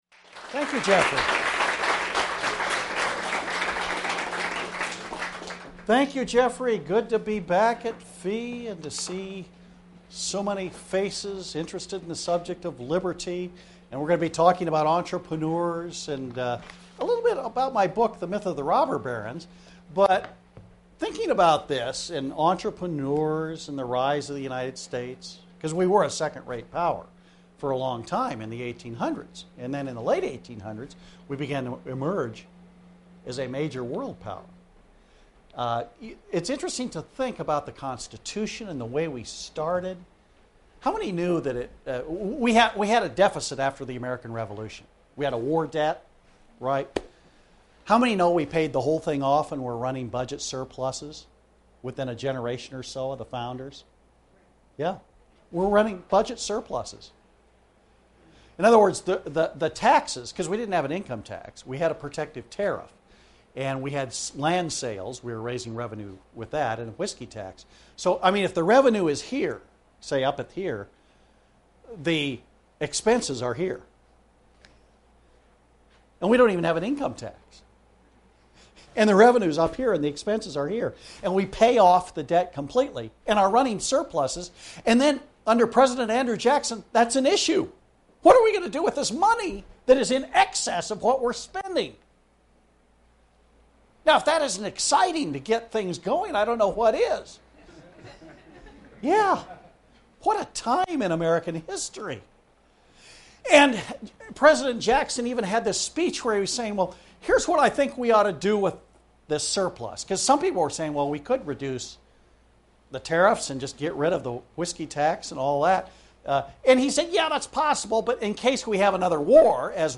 This lecture was given to students attending 2010 History and Liberty in Atlanta, Ga.